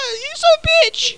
home *** CD-ROM | disk | FTP | other *** search / Horror Sensation / HORROR.iso / sounds / iff / u_bitch.snd ( .mp3 ) < prev next > Amiga 8-bit Sampled Voice | 1992-12-21 | 26KB | 1 channel | 22,095 sample rate | 1 second